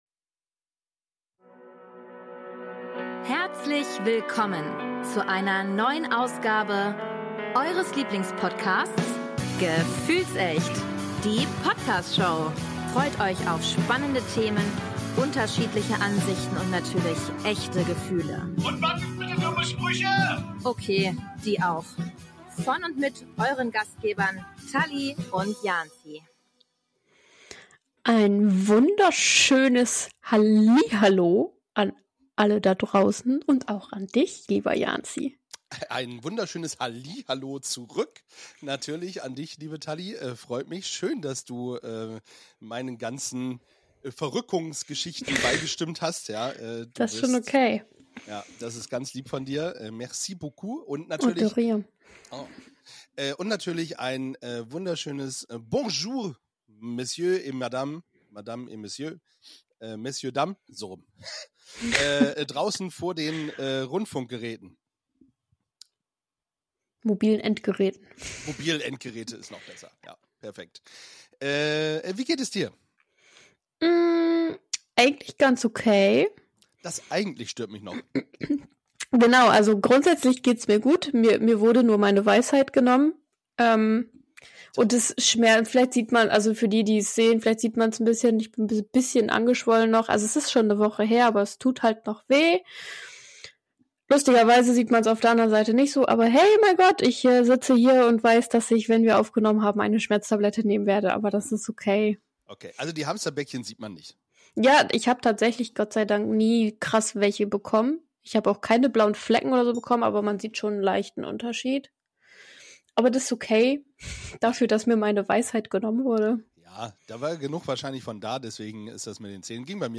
Eigentlich sollte es eine gemütliche Gesprächsrunde werden